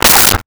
Dog Barking 05
Dog Barking 05.wav